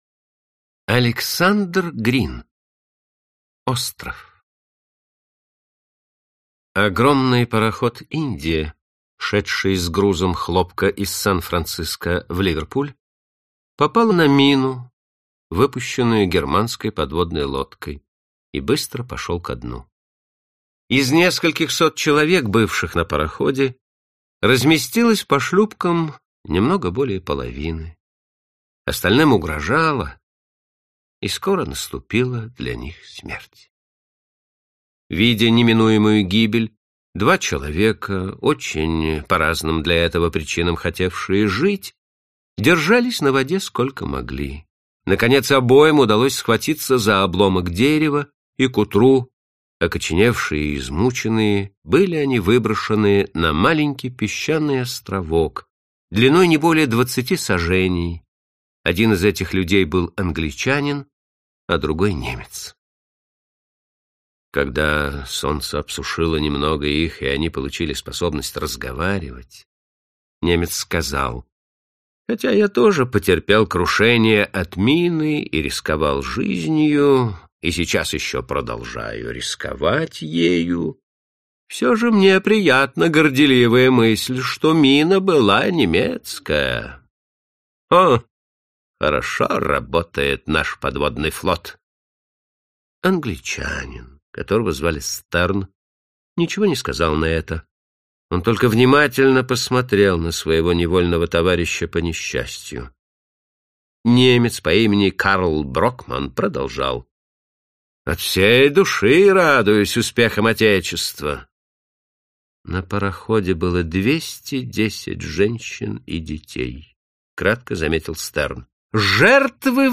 Аудиокнига Классика русского рассказа № 15 | Библиотека аудиокниг